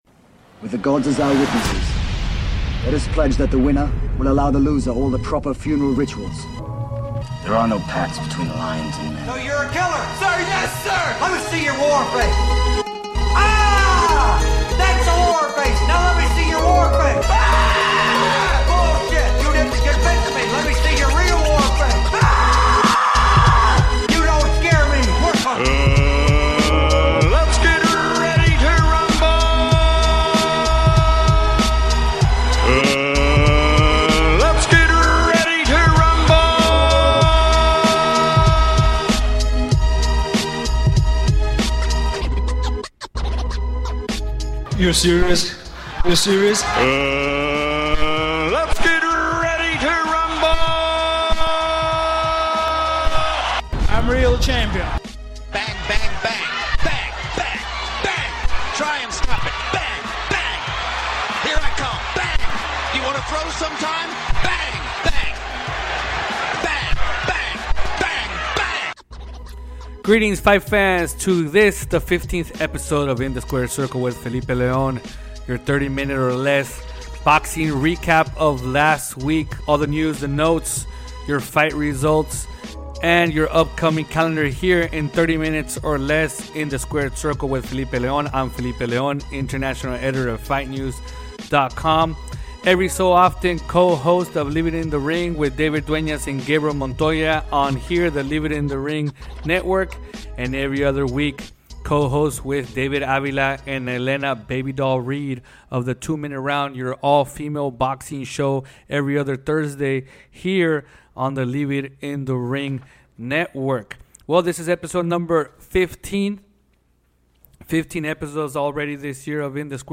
passes the latest news in the sport with a fast pace style of 30 minutes or less